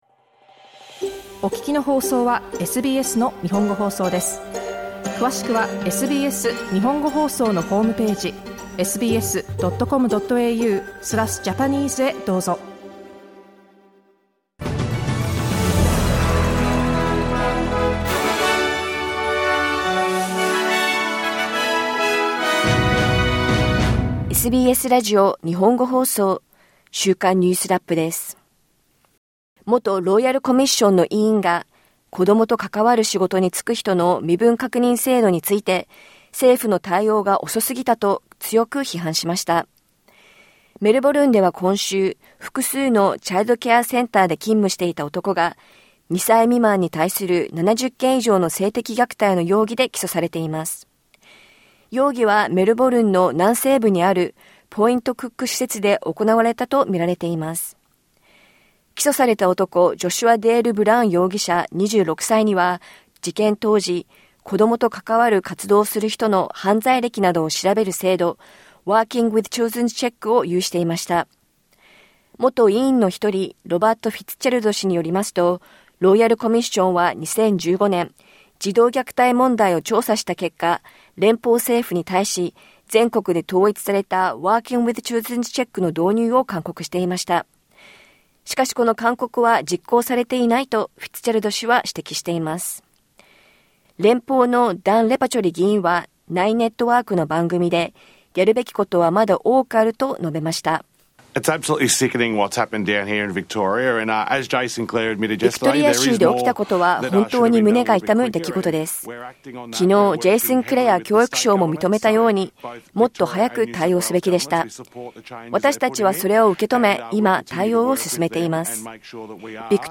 ゴールドコースト出身の日系スケートボード選手、アリサ・トゥルーさんが、Xゲームズで、記録を更新しました。1週間を振り返るニュースラップです。